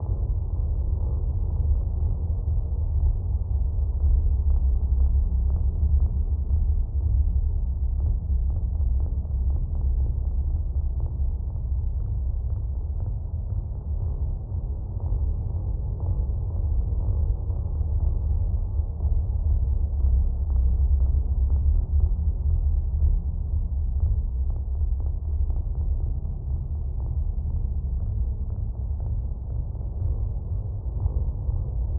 PocketDimensionAmbience.mp3